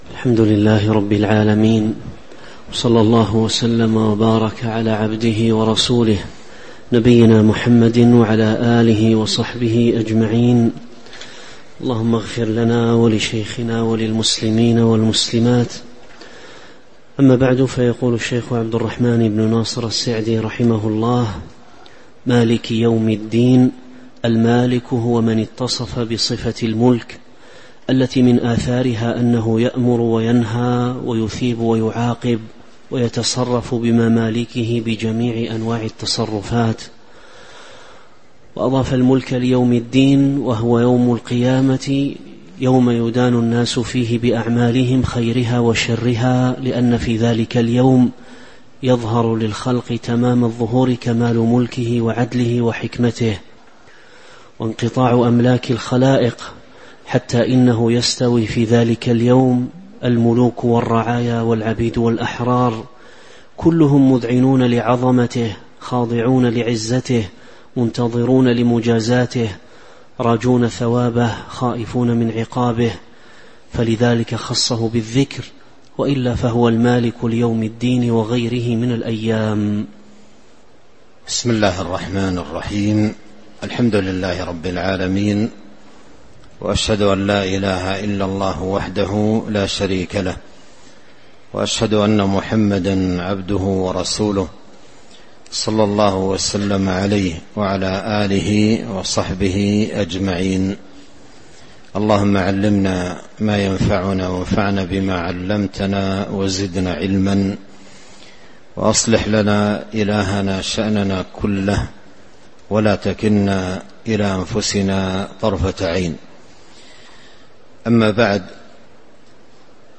تاريخ النشر ١٥ ربيع الأول ١٤٤٦ هـ المكان: المسجد النبوي الشيخ